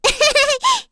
Cecilia-Vox_Happy1.wav